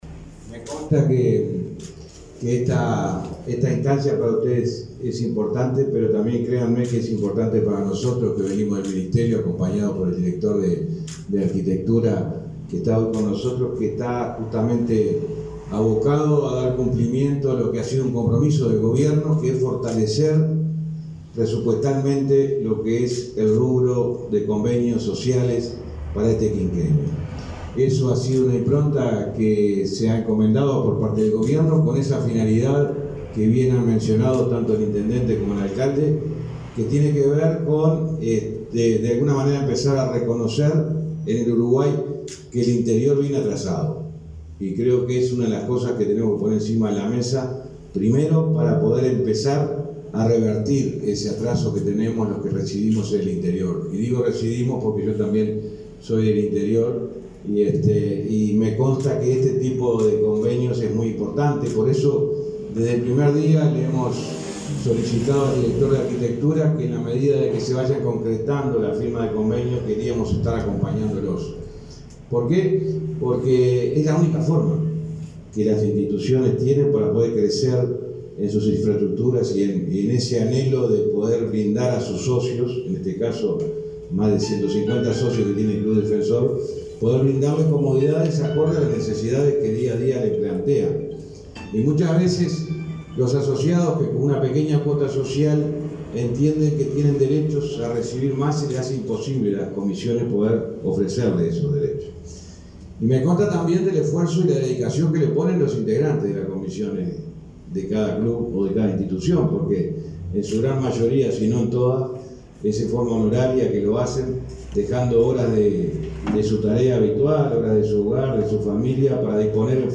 Palabras del ministro de Transporte, José Luis Falero